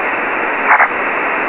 Here are some very short audio examples of meteor scatter: Two doubles which are subsequent pings of different (but locally near-by) stations scattered by the same meteor trail, and a twin-double.